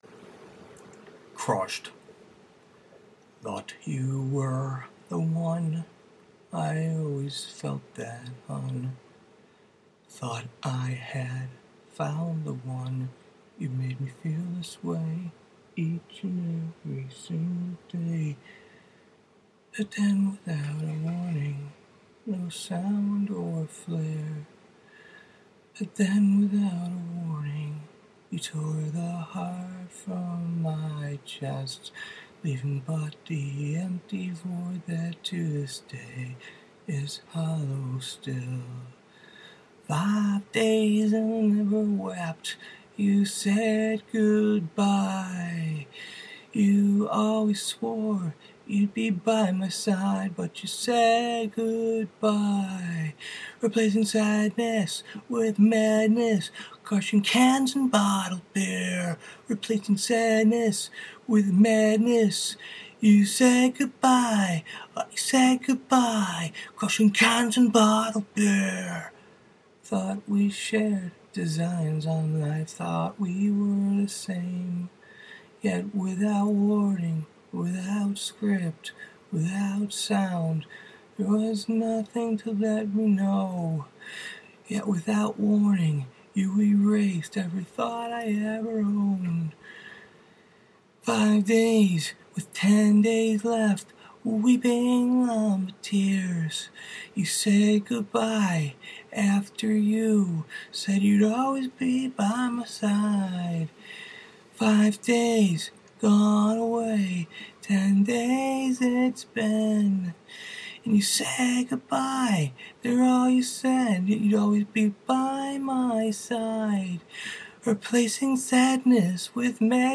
Song, Singing, Vocals, Lyrics, Recording